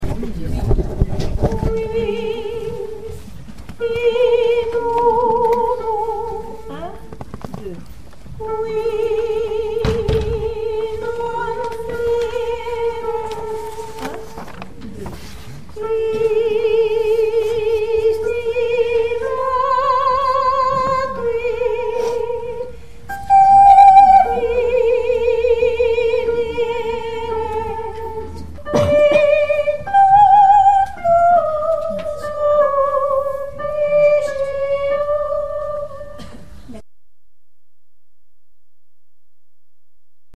5 soprani qui est mes 12 a 17